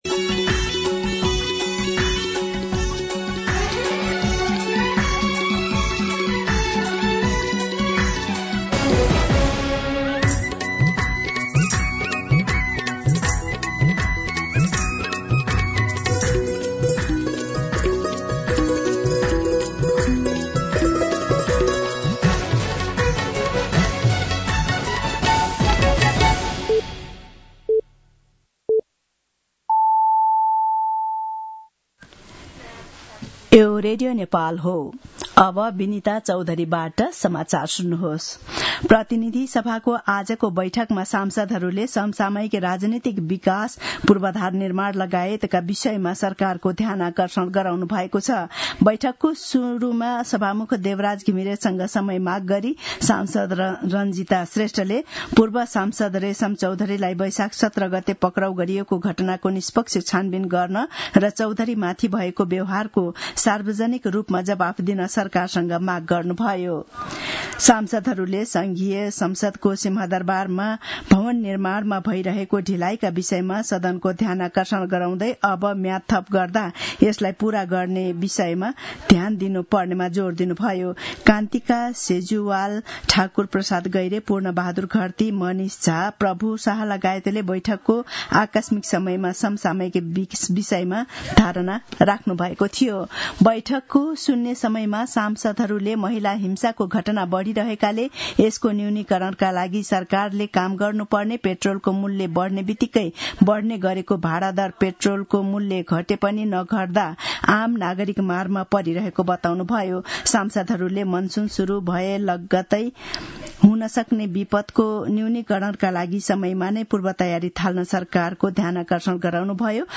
दिउँसो १ बजेको नेपाली समाचार : २३ वैशाख , २०८२